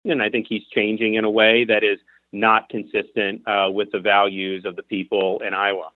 DeSantis made his comments this (Monday) afternoon during an interview with Radio Iowa.